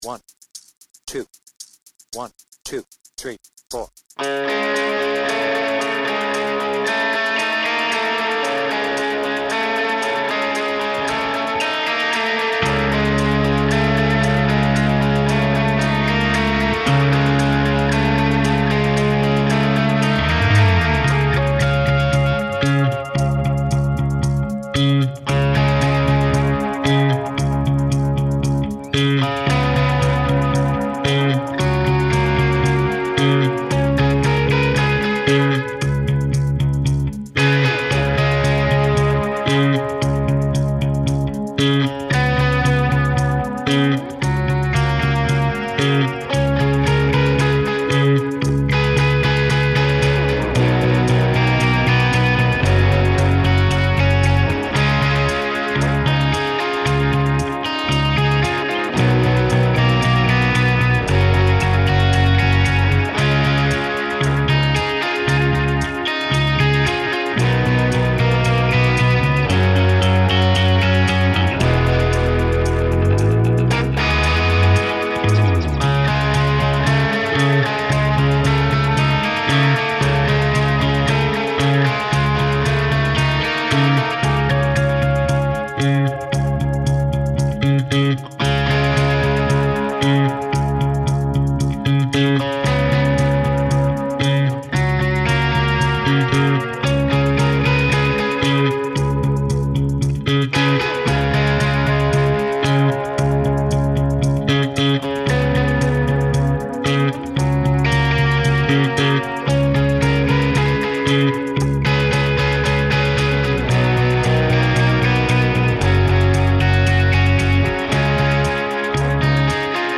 Without vocals
Based on the Vertigo Tour